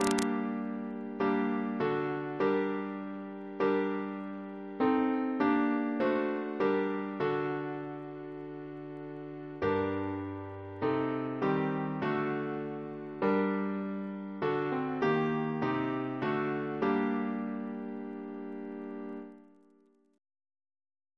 Double chant in G minor Composer: Richard Farrant (d.1580), Organist of St. George's Windsor Reference psalters: ACP: 14